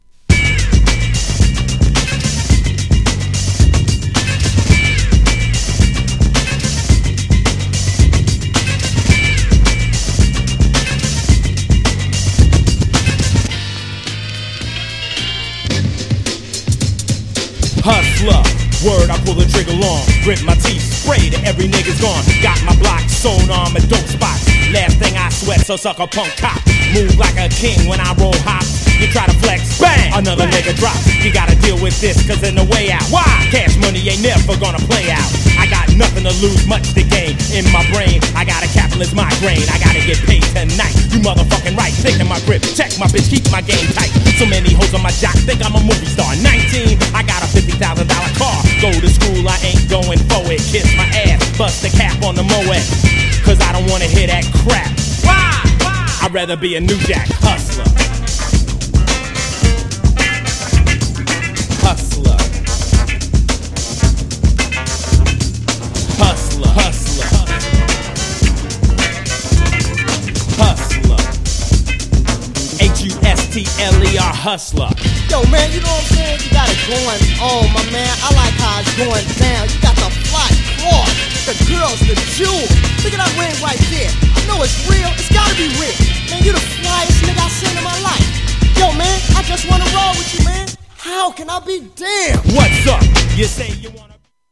HIP HOP/
GENRE Hip Hop
BPM 96〜100BPM